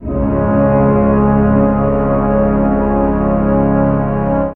55aa-orc04-a#1.wav